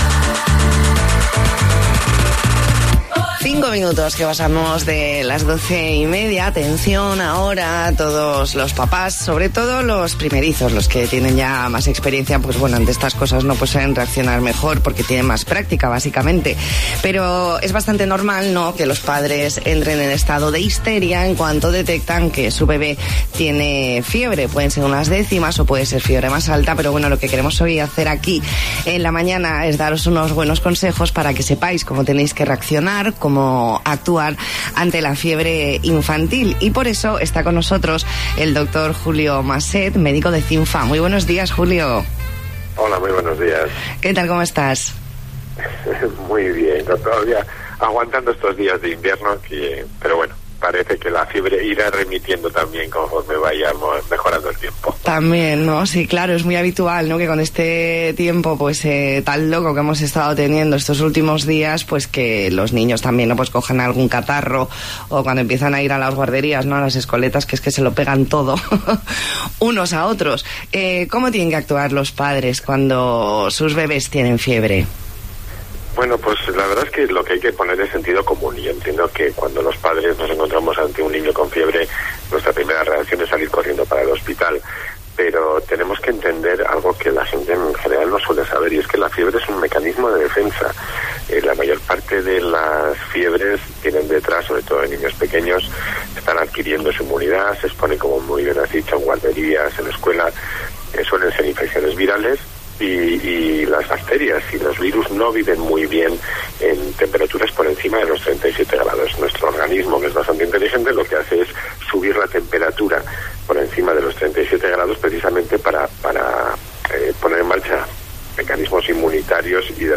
Entrevista en La Mañana en COPE Más Mallorca, jueves 23 de enero de 2020.